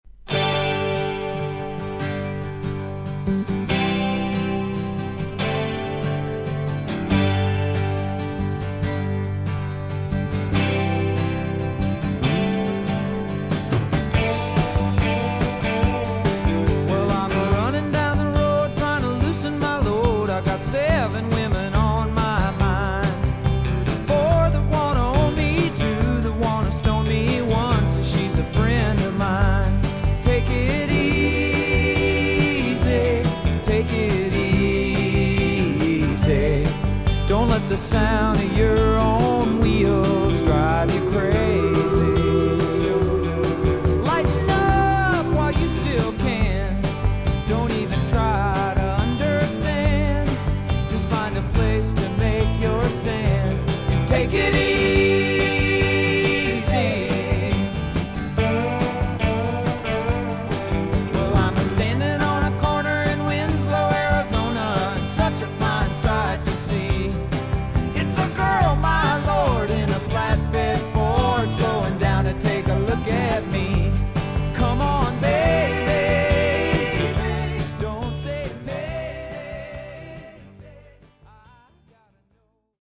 少々軽いパチノイズの箇所あり。少々サーフィス・ノイズあり。クリアな音です。